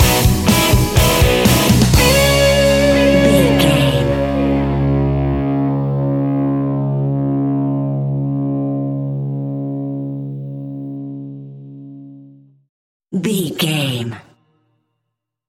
Uplifting
Ionian/Major
D♭
hard rock
heavy metal
blues rock
Rock Bass
Rock Drums
heavy drums
distorted guitars
hammond organ